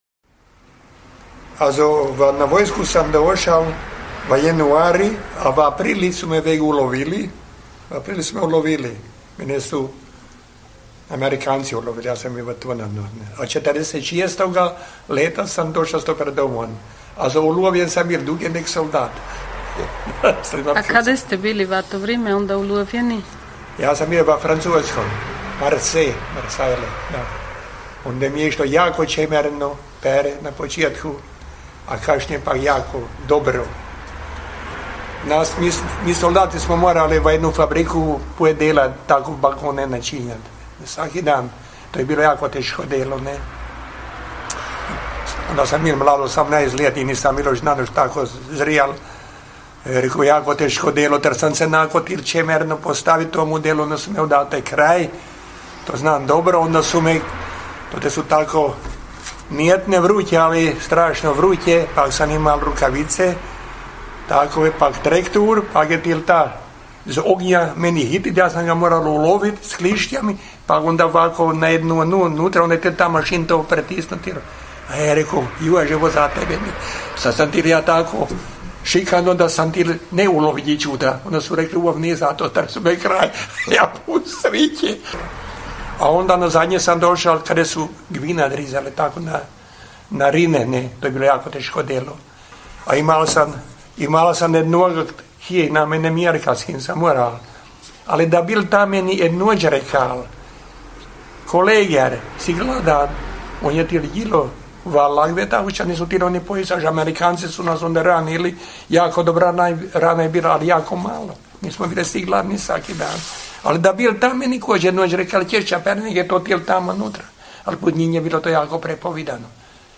jezik naš, jezik naš gh dijalekti
Dolnja Pulja – Govor